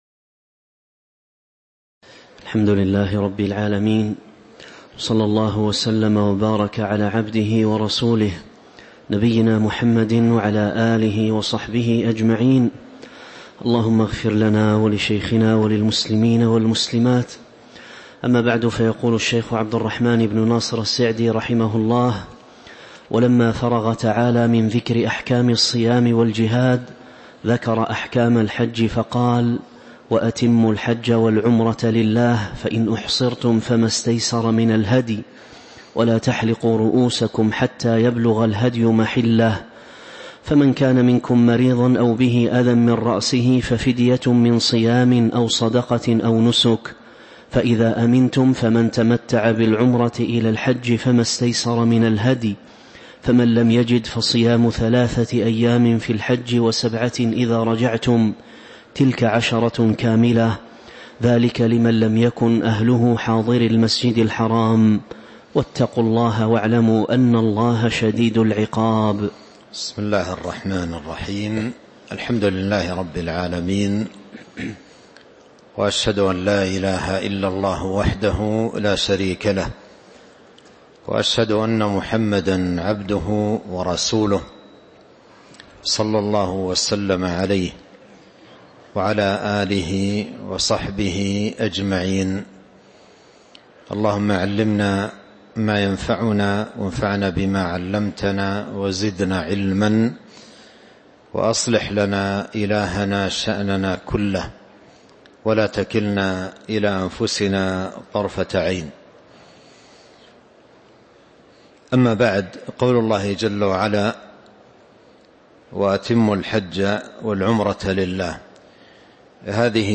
تاريخ النشر ٢٢ رجب ١٤٤٦ هـ المكان: المسجد النبوي الشيخ